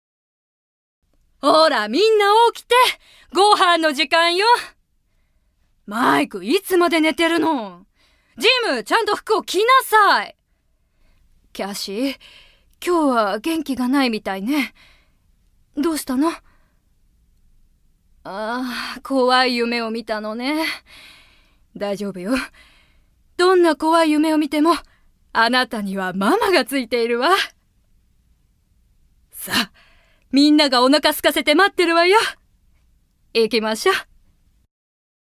◆幼女◆
◆40代女性◆